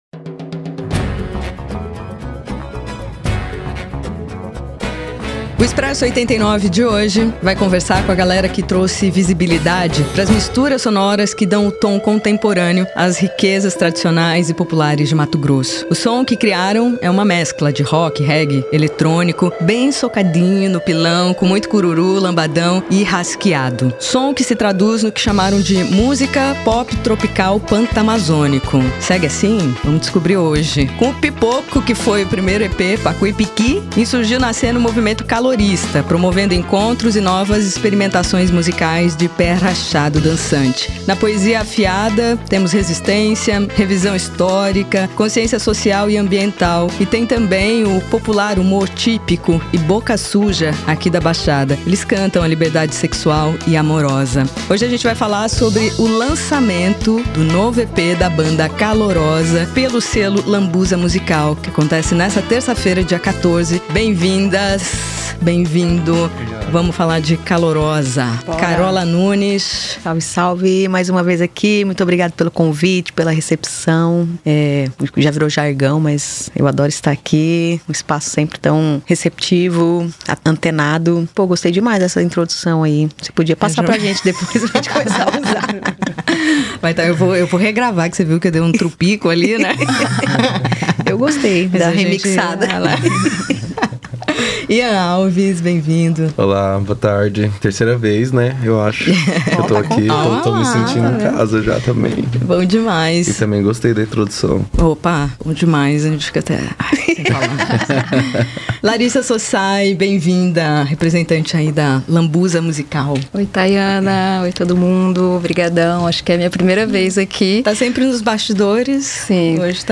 para um bate-papo especial sobre o lançamento do EP “De Emetê (DMT)”, que chega nesta terça-feira (14/10) em todas as plataformas de streaming!